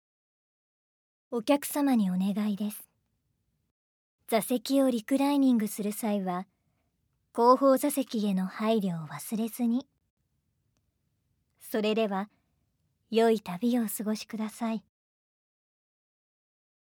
◆台詞７◆